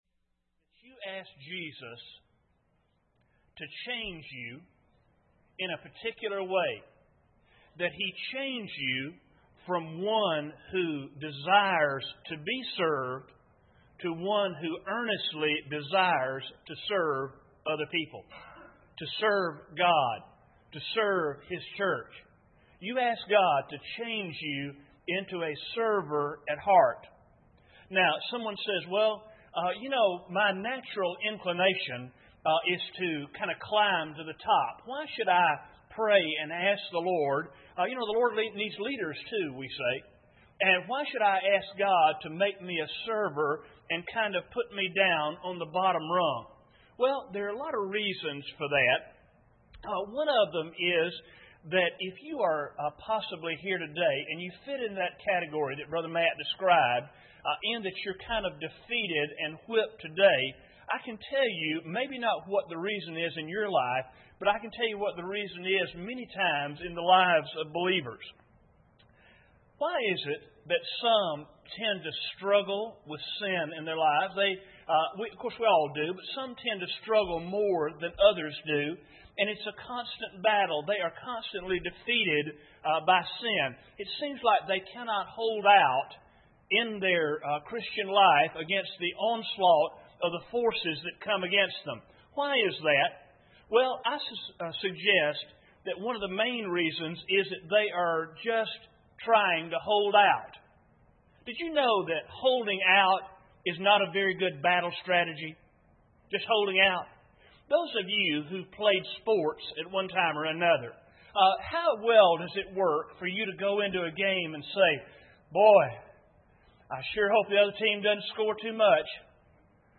Mark 10:35-45 Service Type: Sunday Morning Bible Text